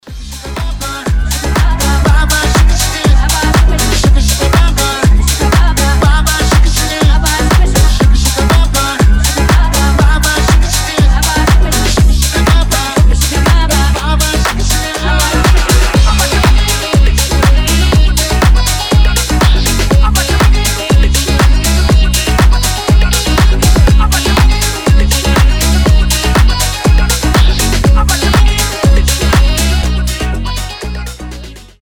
• Качество: 320, Stereo
house